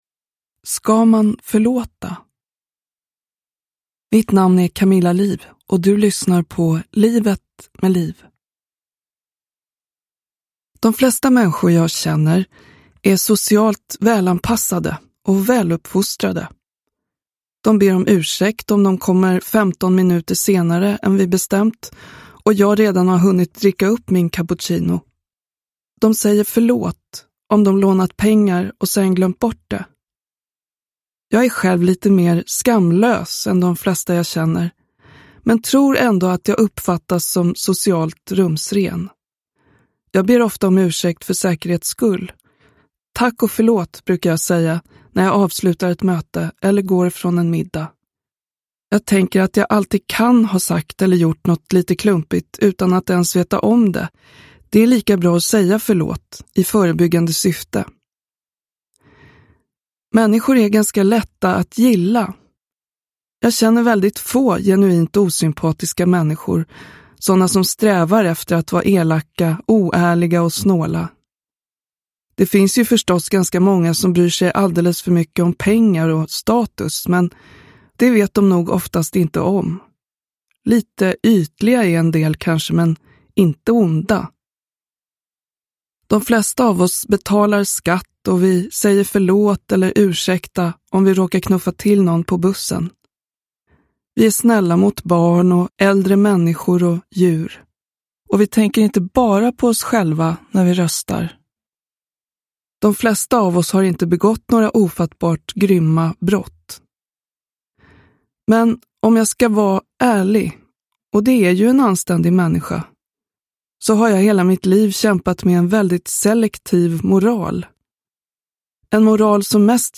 – Ljudbok – Laddas ner